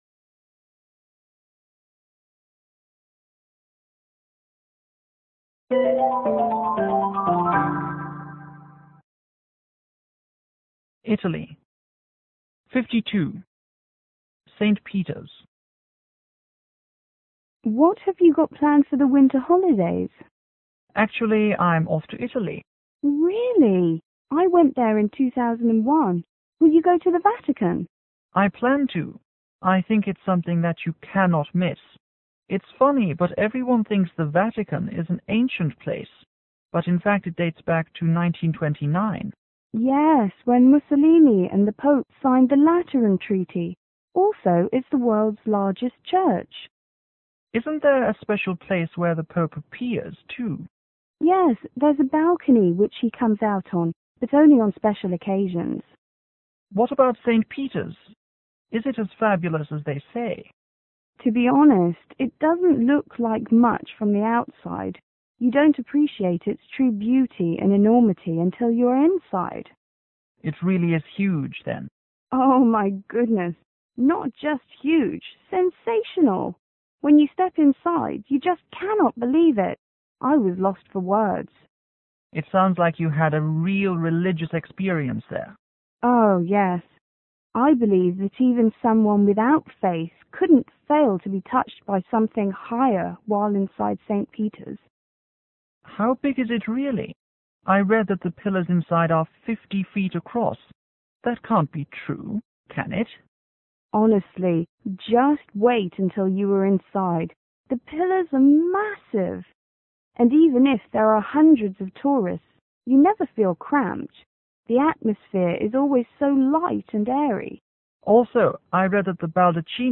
Fl :  Friend l       F2 :  Friend 2